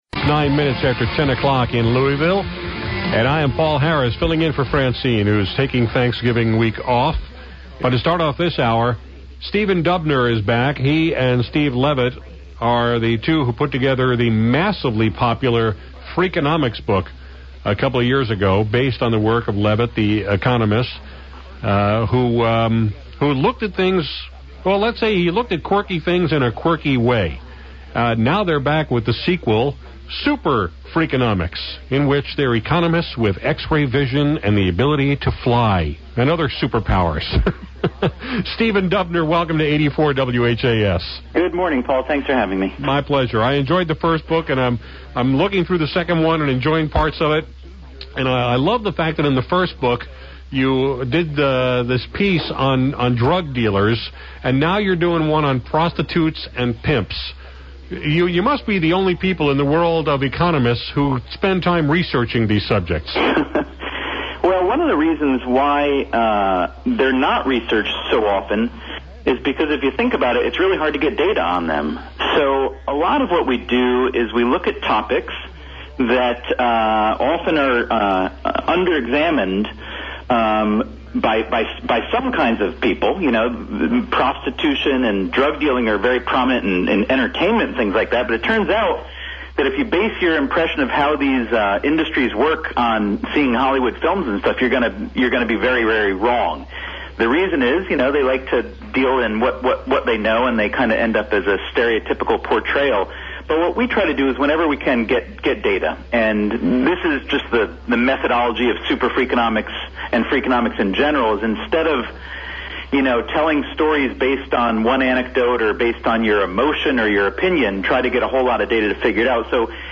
“SuperFreakonomics” has just hit the book shelves, and Dubner joined me to talk about it.